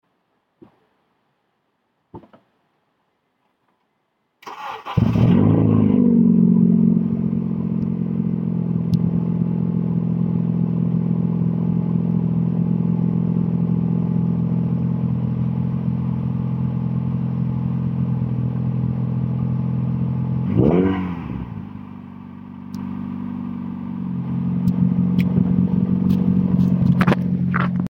skunk2 racing muffler sounds good sound effects free download
skunk2 racing muffler sounds good on a Honda :)